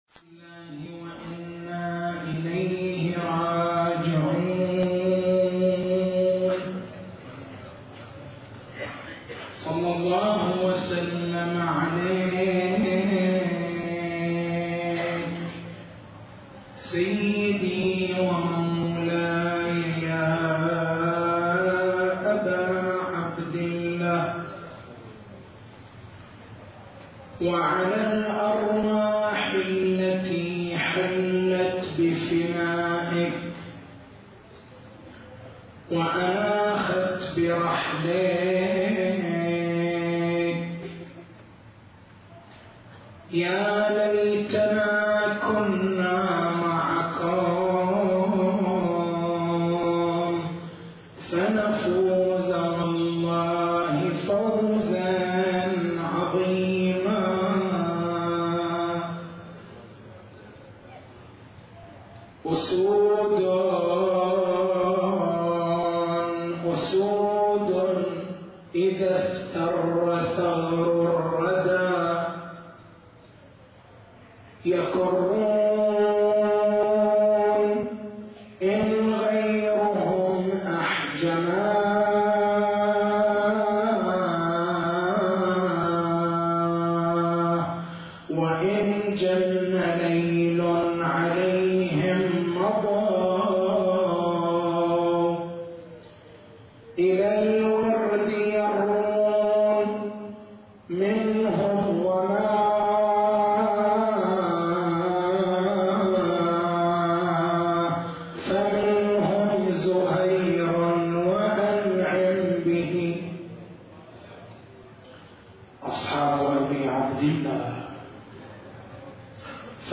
تاريخ المحاضرة: 06/01/1431 نقاط البحث: بيان الأسس العلمية التي اعتمدتها الحوزات لأجل حفظ الدين استمرار الحركة الاجتهادية هل سدّ باب الاجتهاد في الضروريات والمسلّمات إرهابٌ فكريٌّ؟